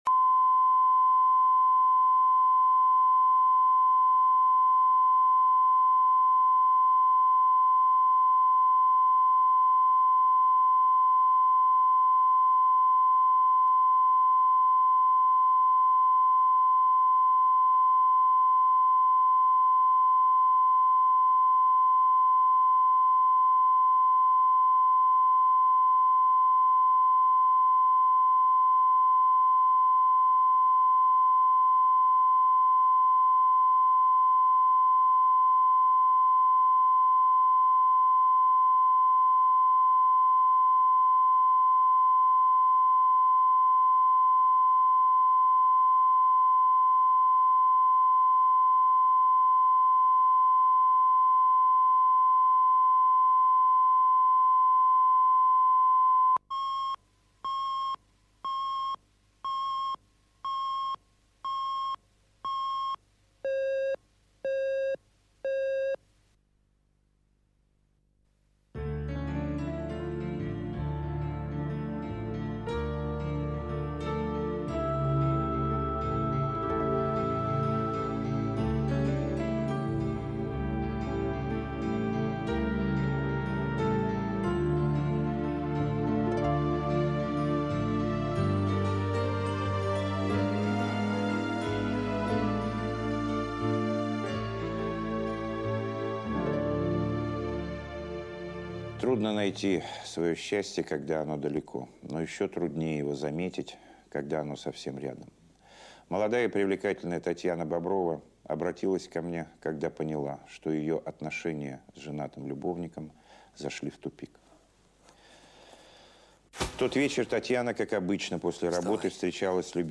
Аудиокнига Счастье близко, счастье далеко | Библиотека аудиокниг